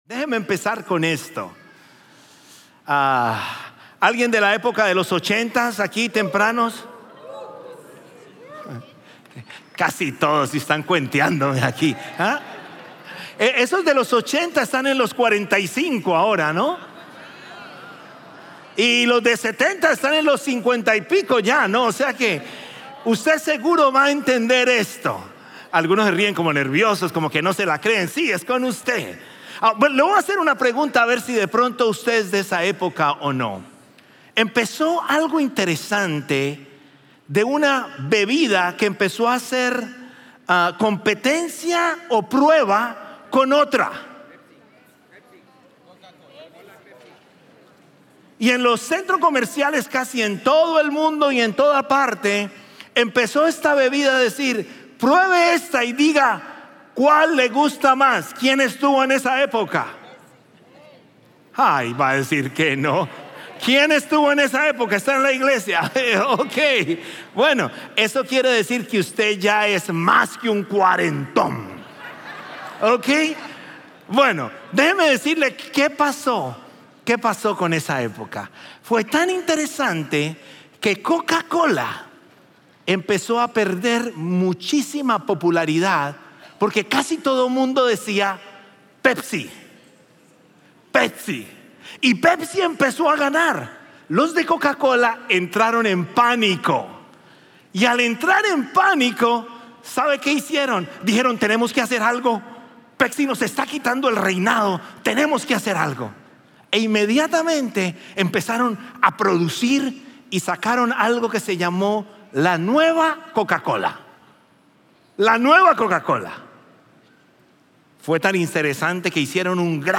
Un mensaje de la serie "Evidencias - JV ."